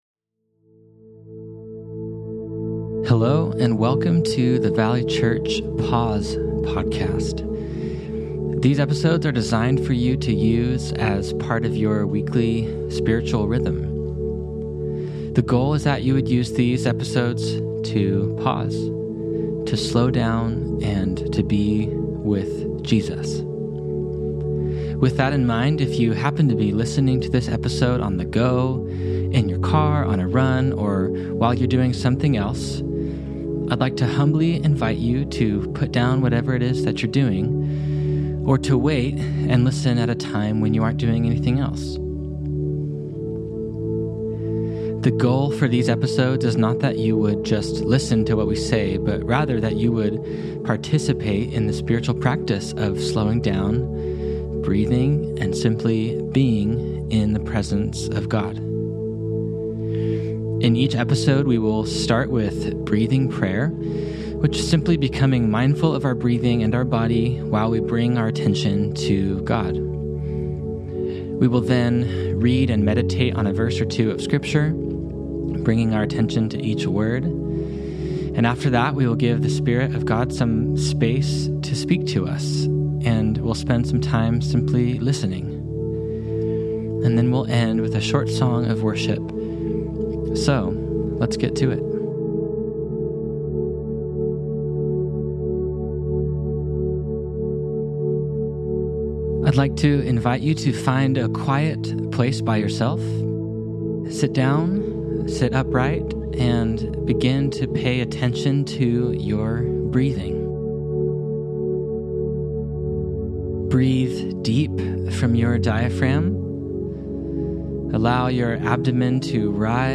Breathing prayer exercise adapted from Pete Scazzero’s Emotionally Healthy Relationships: Day by Day, Appendix B.